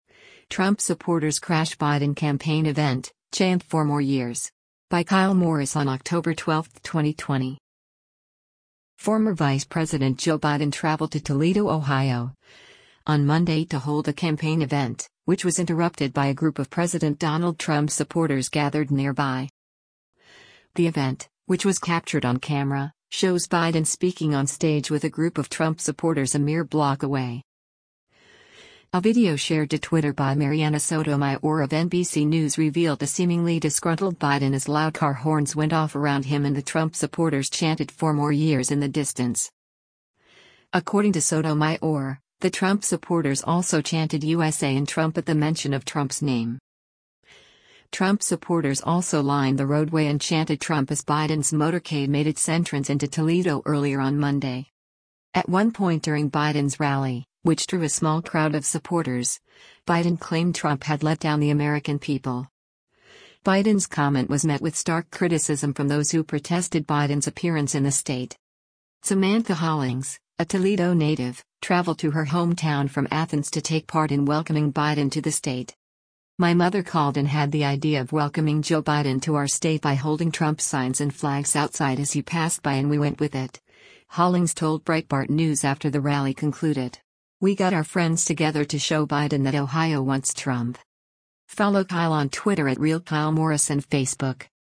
Trump Supporters one Block from Biden Rally
The event, which was captured on camera, shows Biden speaking on stage with a group of Trump supporters a mere block away.